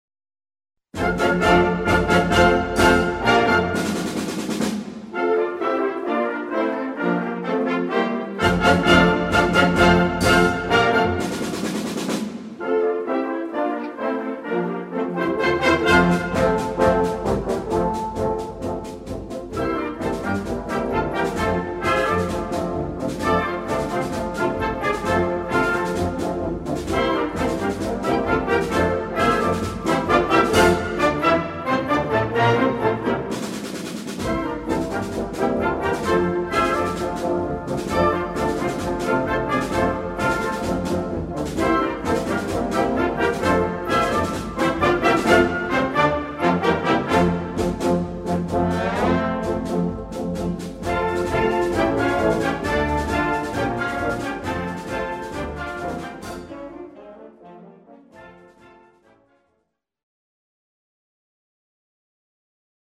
Répertoire pour Harmonie/fanfare - Défilé et parade